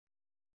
♪ bēṭakāṛa